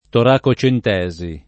toracentesi [ tora © ent $@ i ; alla greca tora ©$ nte @ i ]